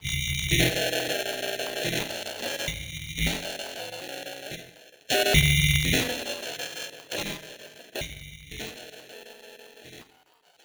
Abstract Rhythm 25.wav